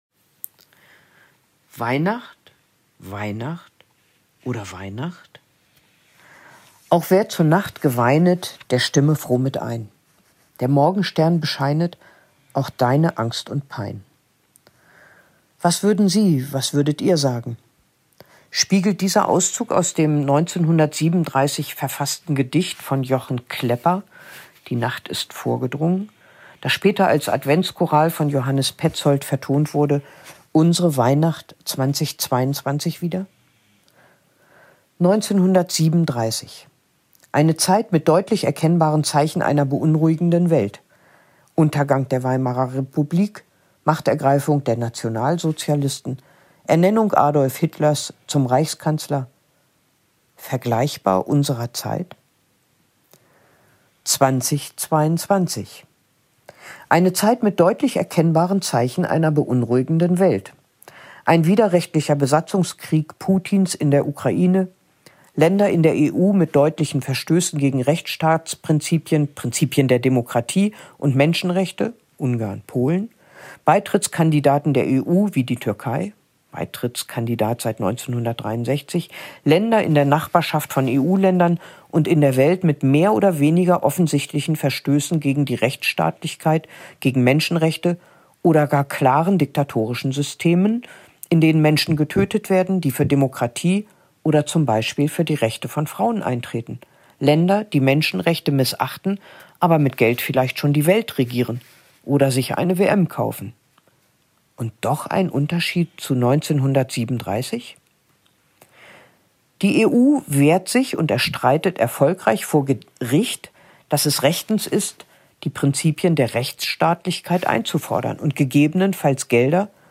Vorwort zum Jahrbuch – Hörfassung